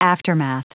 Prononciation du mot aftermath en anglais (fichier audio)
Prononciation du mot : aftermath